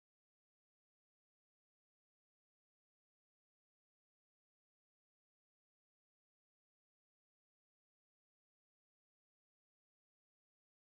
Cats Hop Through a Field of Lavender Flowers (No sound)